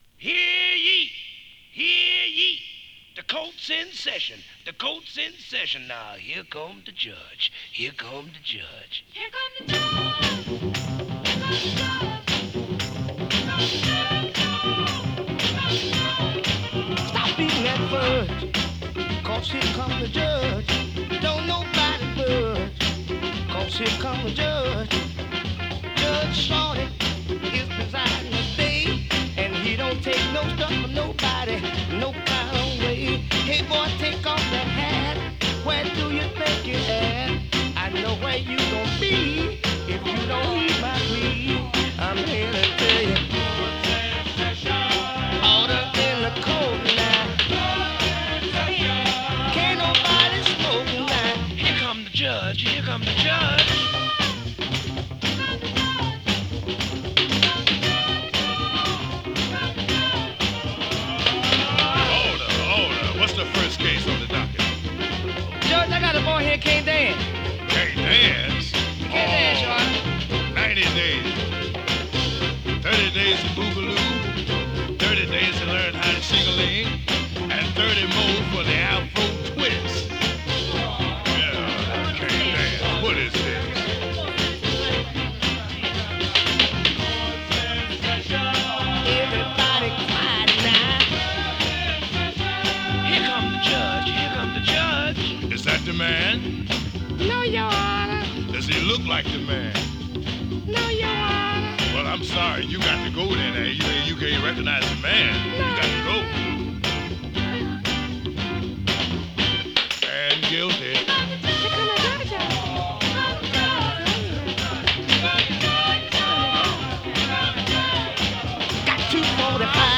60s R＆B ファンキーソウル